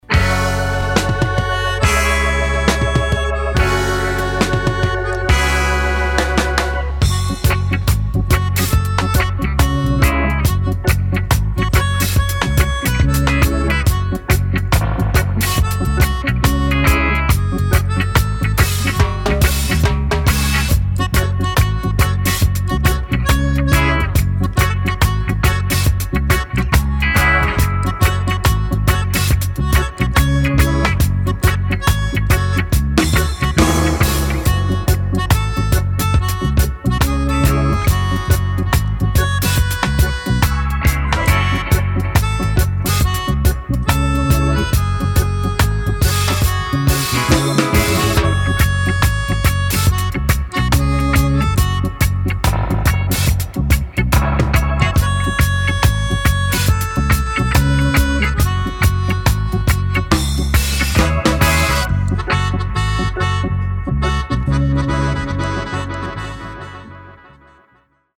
12″ Showcase with Vocals Dubs & Versions.
modern roots steppers
All recorded, mixed & mastered